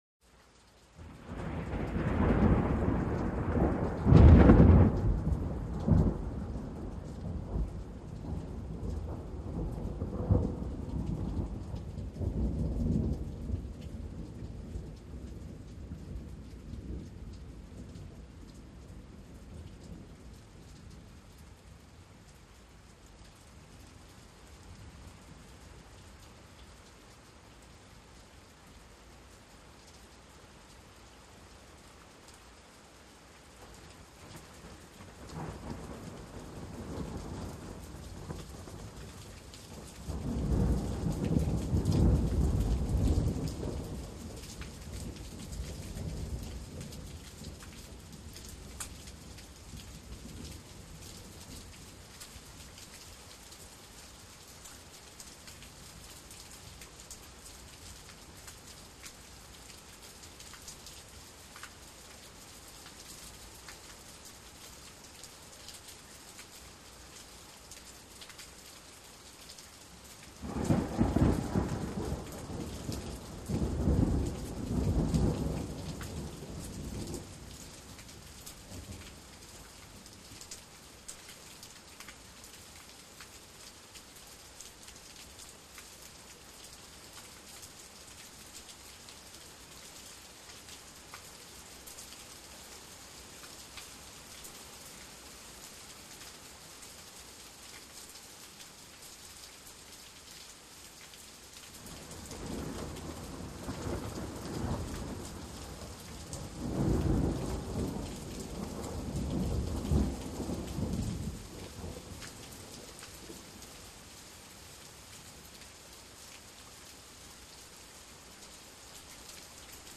Thunder; Rumbles And Cracks In The Distance With Medium, Splatty Rain That Increases In Intensity.